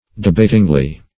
debatingly - definition of debatingly - synonyms, pronunciation, spelling from Free Dictionary Search Result for " debatingly" : The Collaborative International Dictionary of English v.0.48: Debatingly \De*bat"ing*ly\, adv. In the manner of a debate.